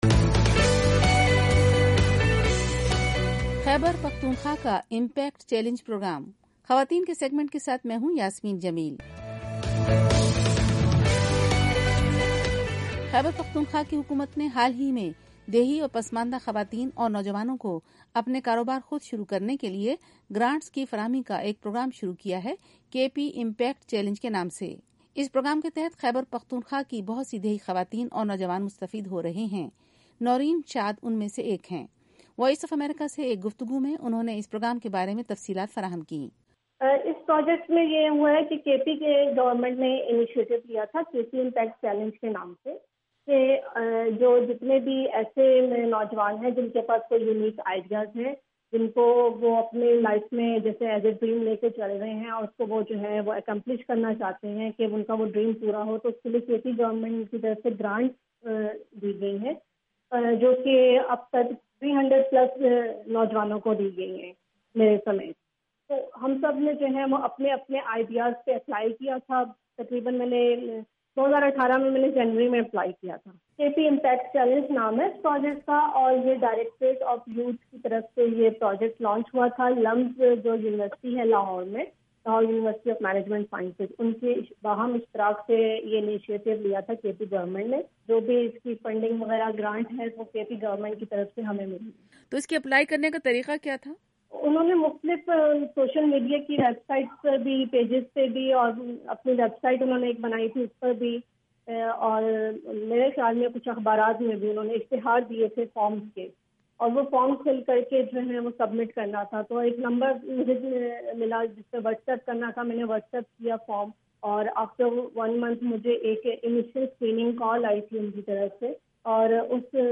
انہوں نے وائس آف امریکہ سے اس پروگرام کے متعلق گفتگو کی۔